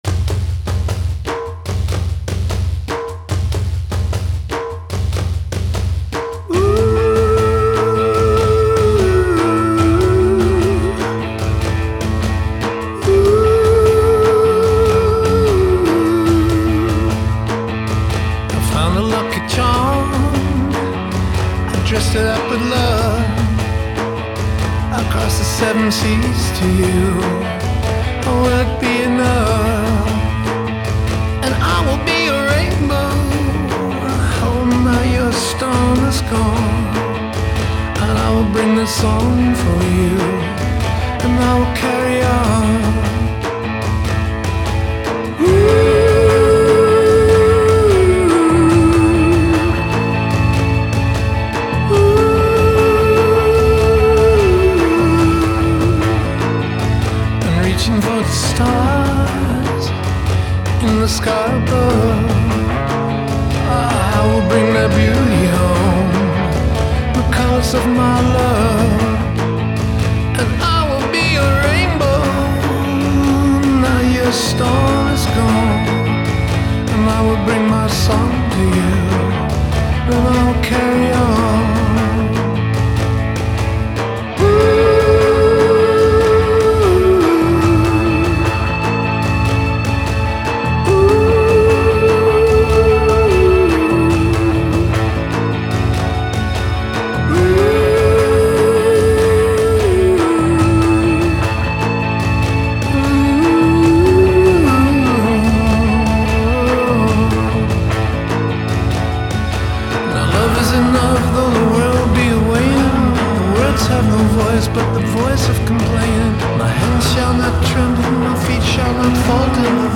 worldly psychedelia, classic folk-blues, and dense rhythms
continues the swirling vibe